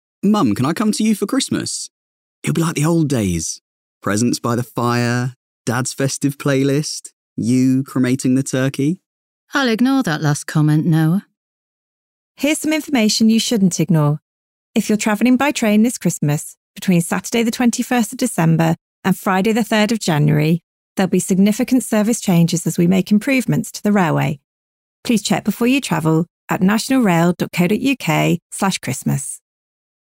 Radio adverts